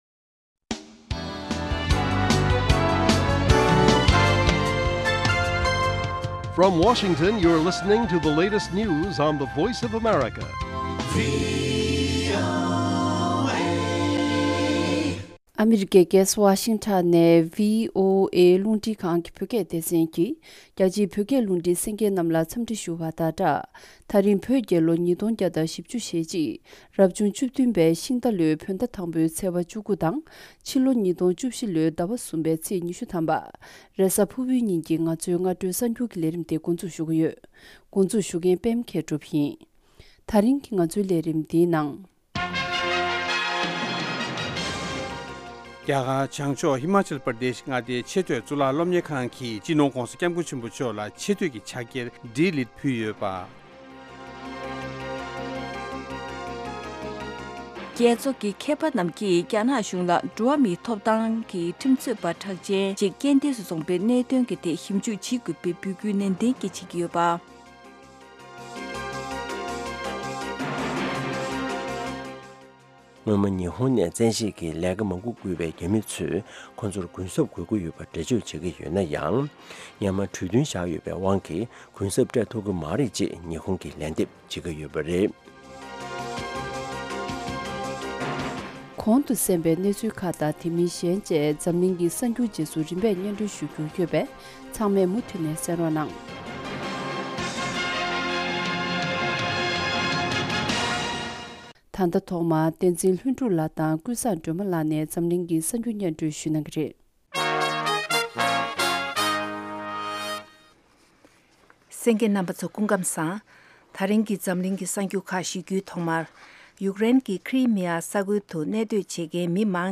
སྔ་དྲོའི་གསར་འགྱུར།